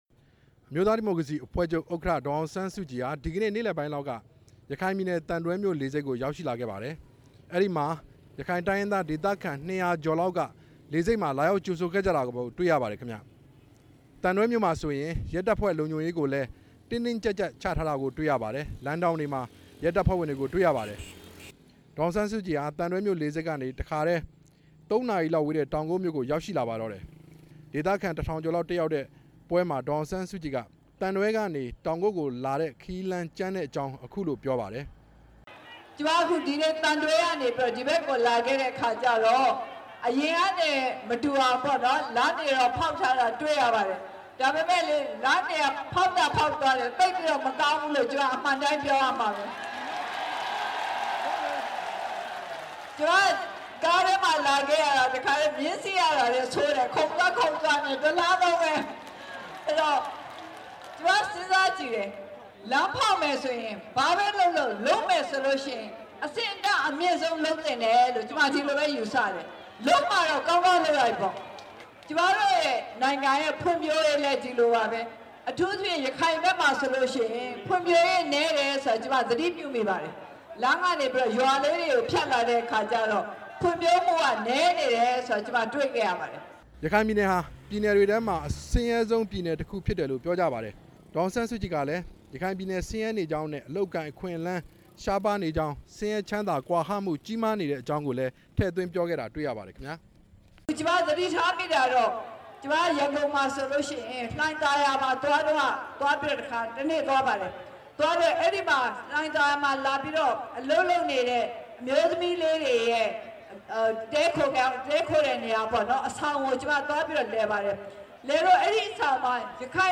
ဒီကနေ့ ရခိုင်ပြည်နယ် တောင်ကုတ်မြို့မှာ မဲဆွယ်စည်းရုံးရေးဟောပြောပွဲမှာ တက်ရောက်သူ တစ်ဦးက ဒေါ်အောင်ဆန်းစုကြည် အနိုင်ရရင် ဘာသာခြားတွေ လွှမ်းမိုးလာမလားဆိုပြီး မေးမြန်းရာ မှာ ဒေါ်အောင်ဆန်းစုကြည် က ဖြေကြားလိုက်တာ ဖြစ်ပါတယ်။